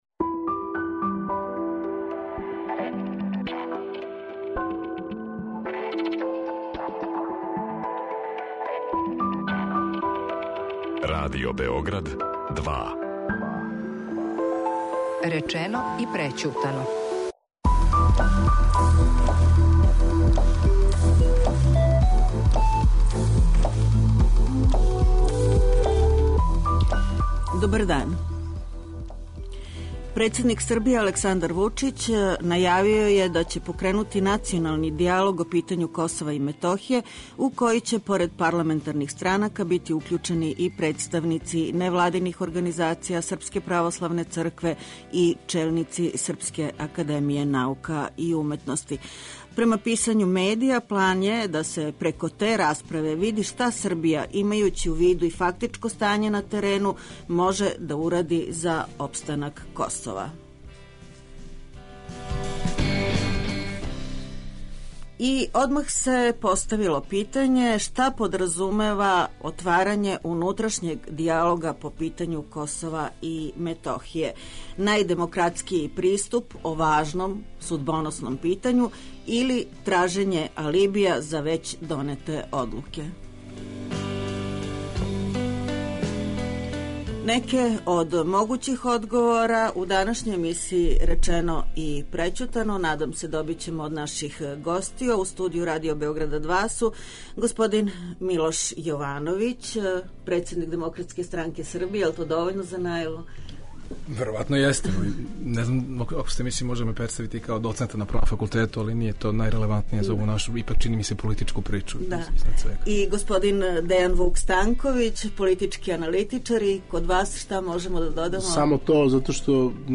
говоре Милош Јовановић, председник ДСС и доцент на Правном факултету
политички аналитичар.